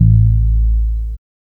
5208R BASS.wav